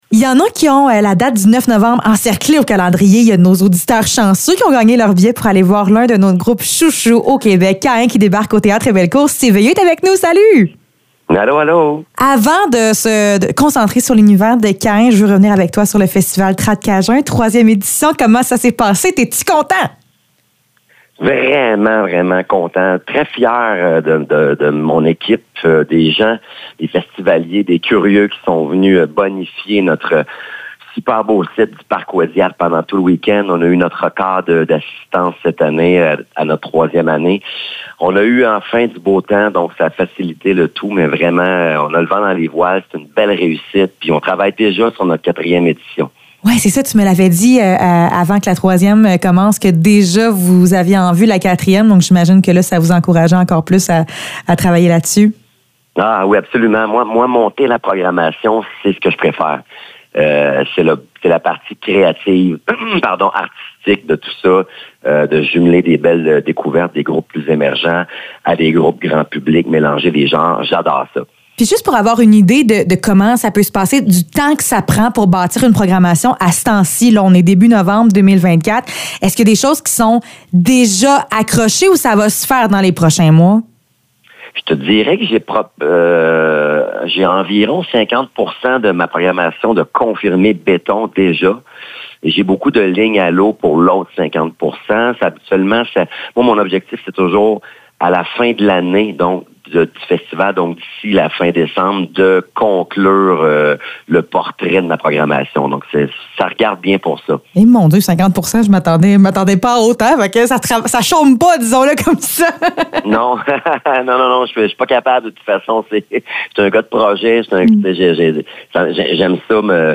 Entrevue avec Steve Veilleux de Kaïn